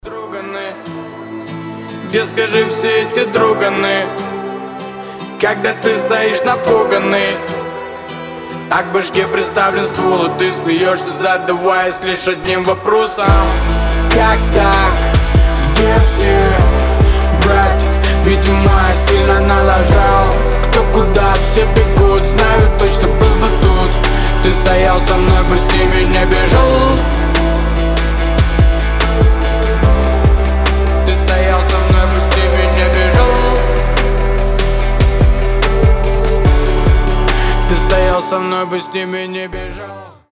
• Качество: 320, Stereo
гитара
лирика
душевные
грустные
ностальгия